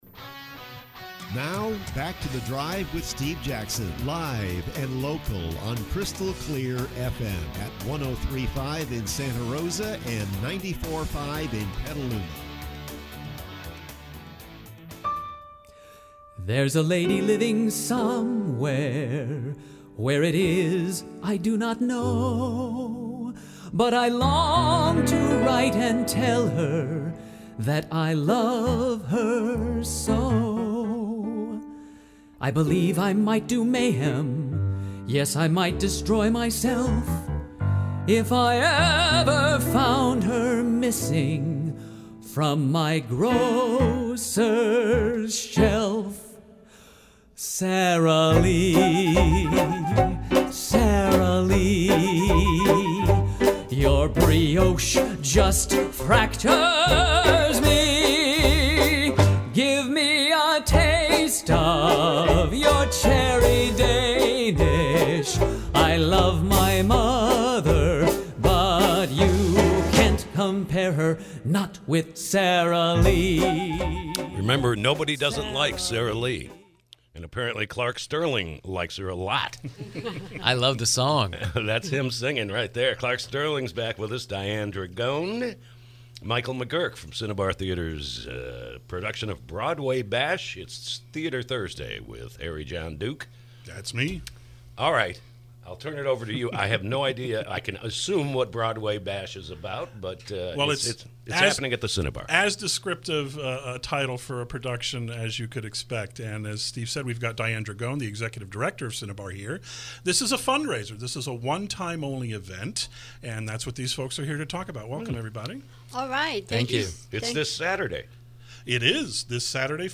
KSRO Interview – Cinnabar’s Broadway Bash